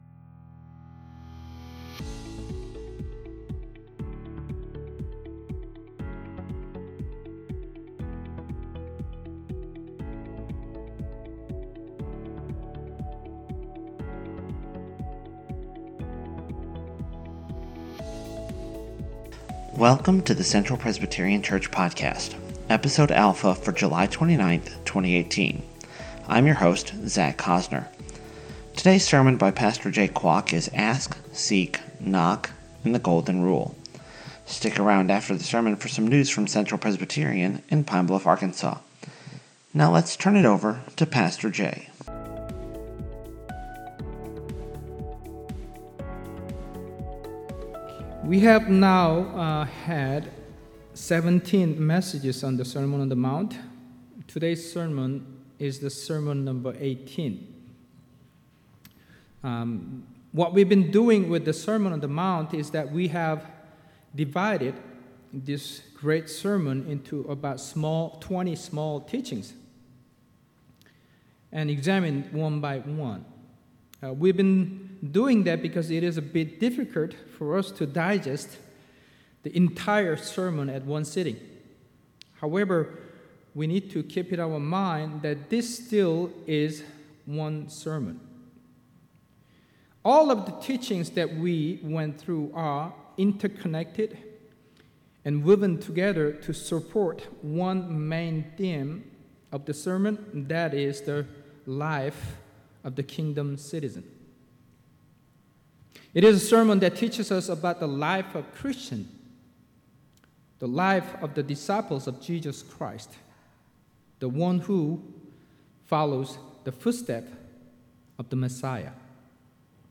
It's our goal to get the sermon up each week in a timely fashion.